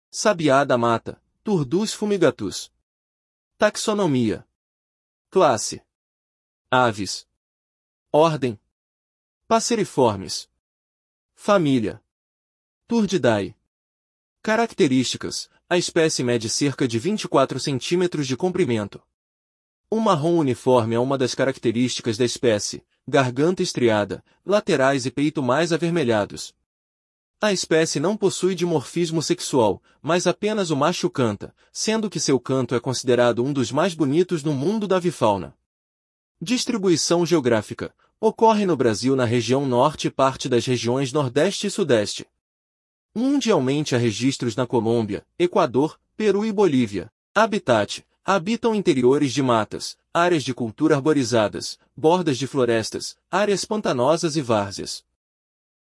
Sabiá-da-mata (Turdus fumigatus)
A espécie não possui dimorfismo sexual, mas apenas o macho canta, sendo que seu canto é considerado um dos mais bonitos no mundo da avifauna.
Curiosidades: Animal que possui um dos cantos mais bonitos da avifauna Brasileira,